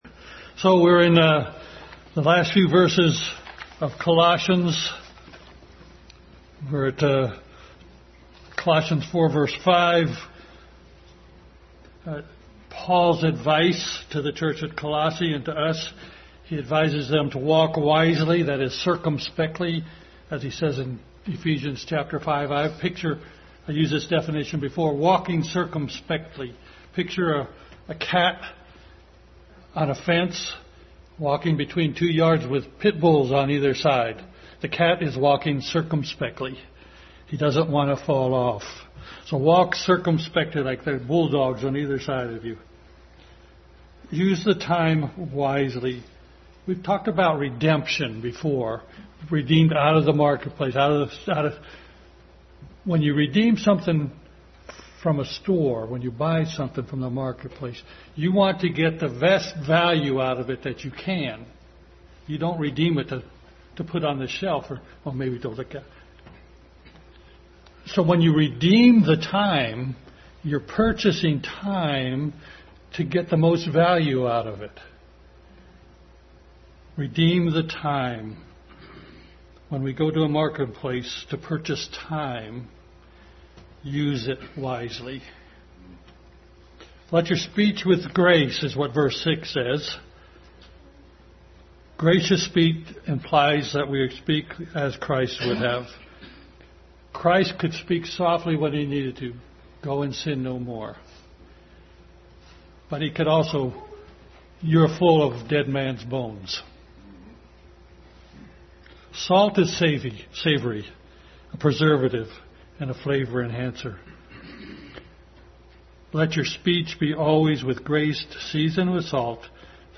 Philemon Passage: Colossians 4:5-18, Philemon Service Type: Family Bible Hour Family Bible Hour message.